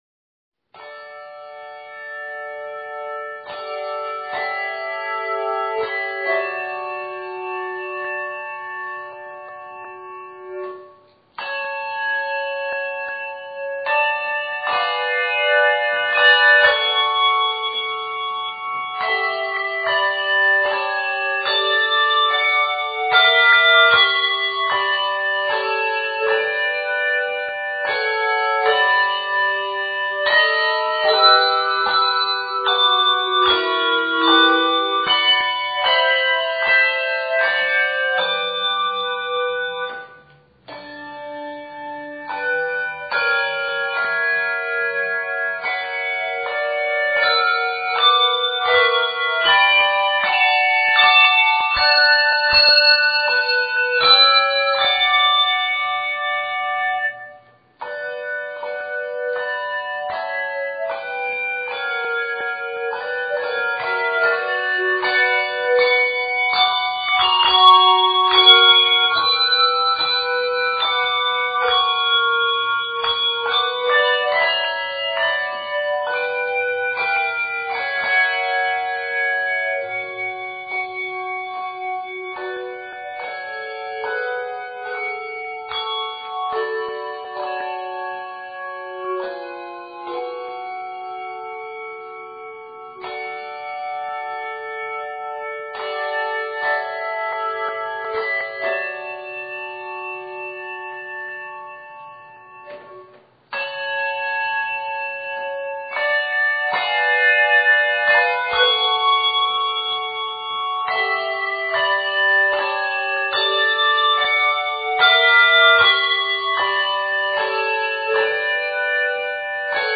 handbell trio setting
An easy-medium trio that is 78 measures in length.